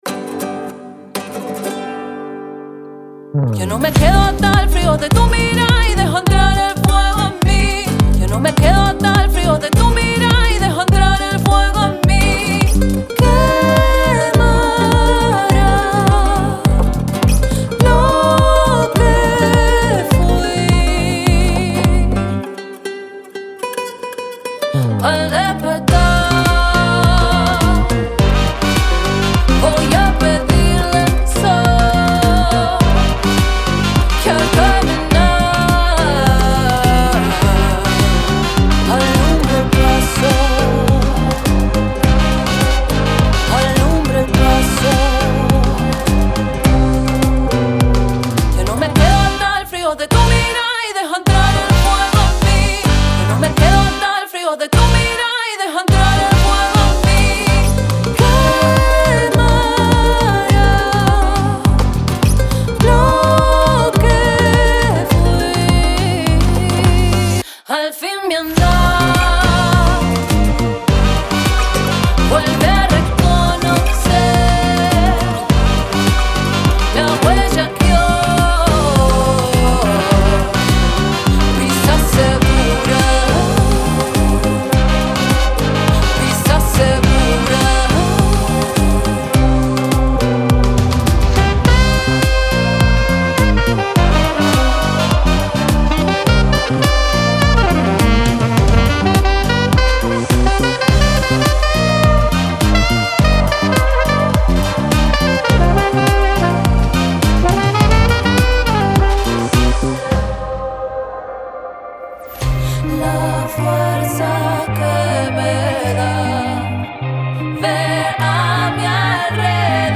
Dance version
Dance-Version